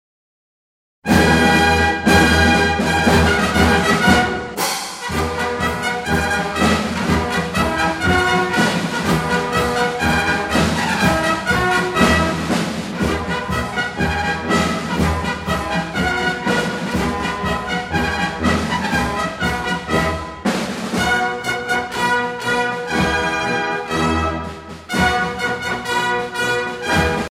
gestuel : à marcher
circonstance : militaire
Catégorie Pièce musicale éditée